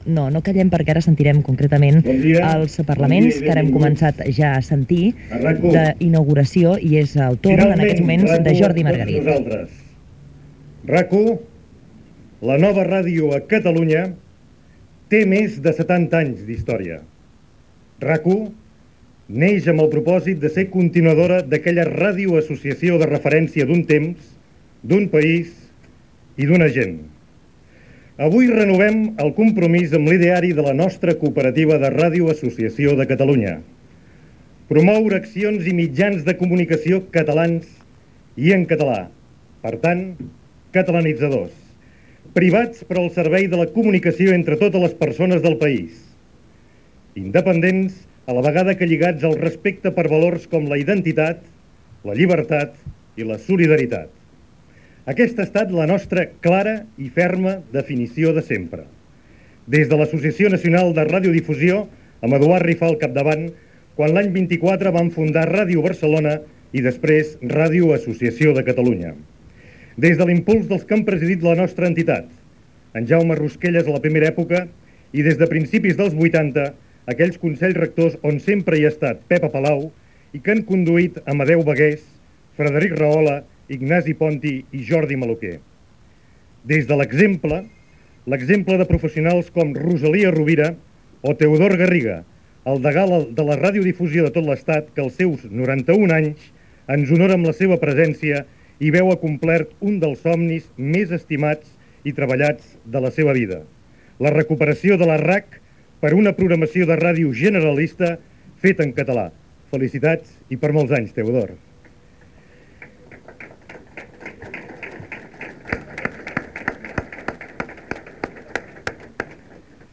Parlament
el dia de la inauguració de l'emissora